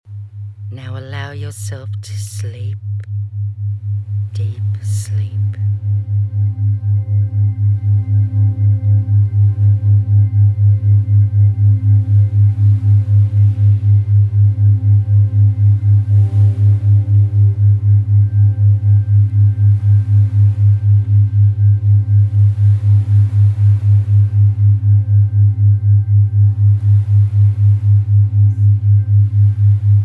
Binaural beats work by sending slightly different sound wave frequencies to each ear, guiding your brain into specific states through brainwave frequency entrainment.
Binaural Beats for Deep Sleep & Relaxation